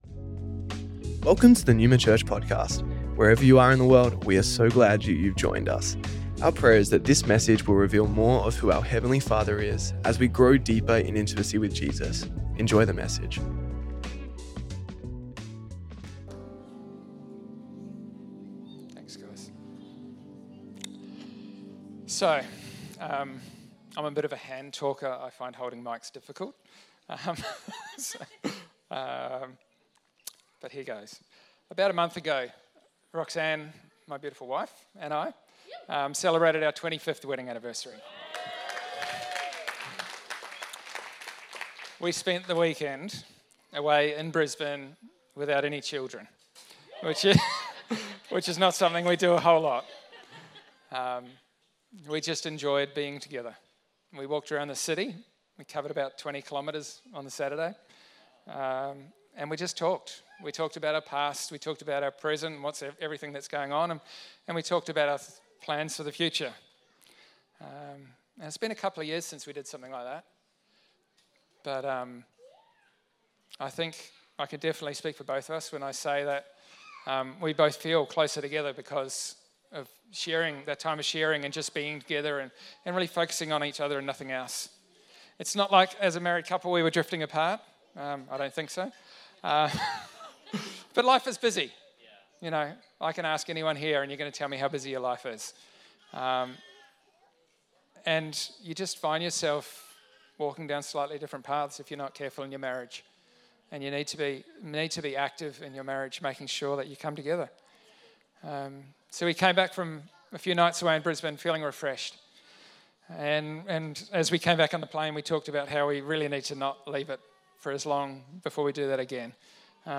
Recorded at Melbourne Neuma West